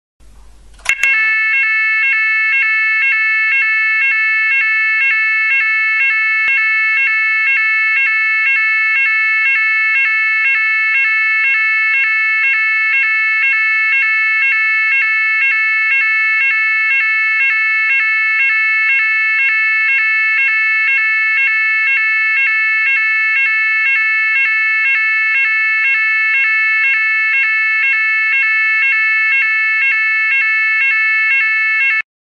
Écoute des signaux d’alarme👂📢🔥🚒⛈🔐
• l’alarme incendie
ALARME-INCENDIE.mp3